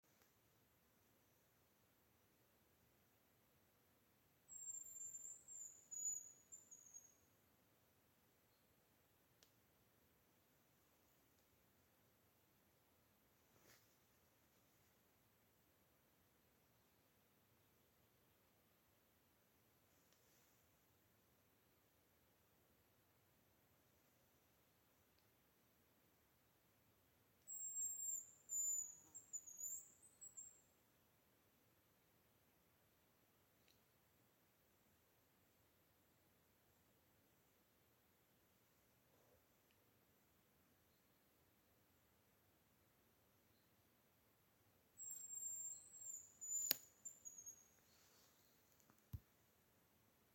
Hazel Grouse, Bonasa bonasia
StatusVoice, calls heard